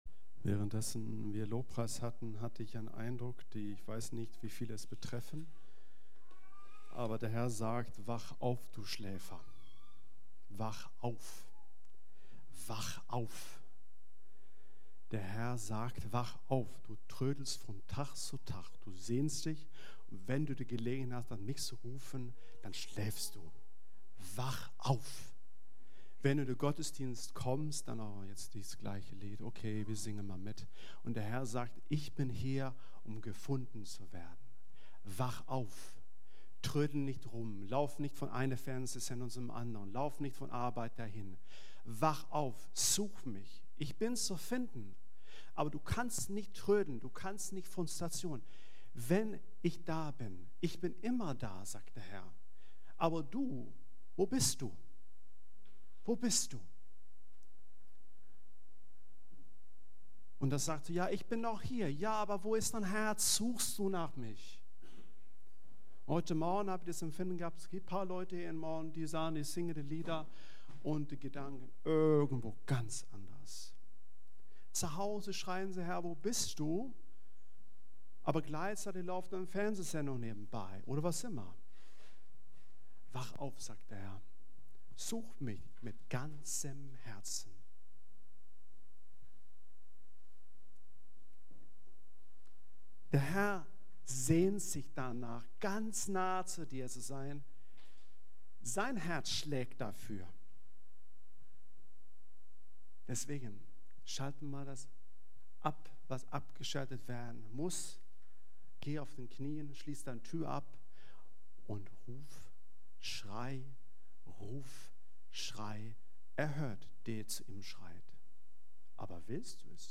Kirche am Ostbahnhof Navigation Infos Über uns…
Predigten